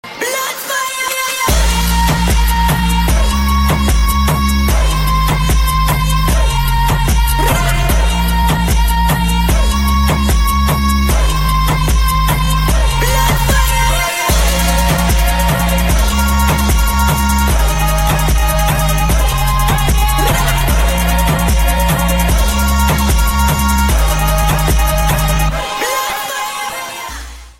громкие
женский вокал
dance
Electronic
EDM